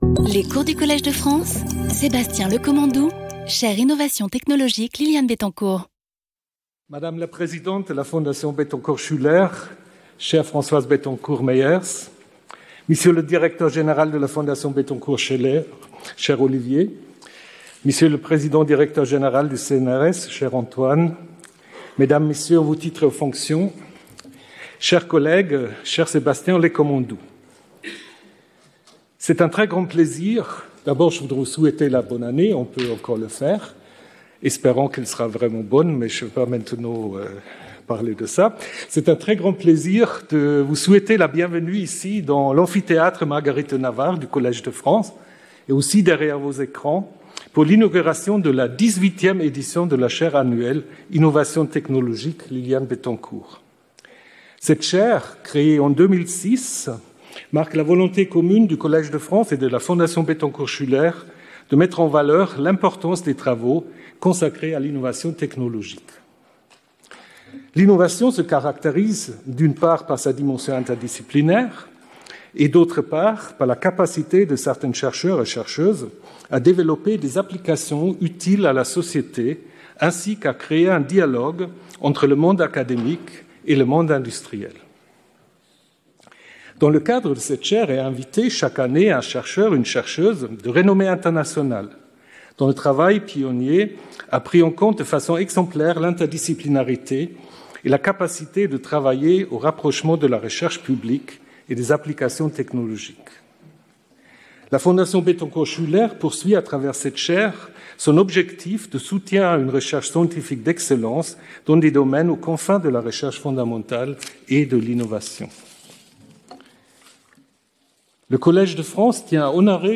Skip youtube video player Listen to audio Download audio Audio recording Abstract This lesson explores recent advances in the field of biopolymers, in particular biomimetic and biohybrid polymers.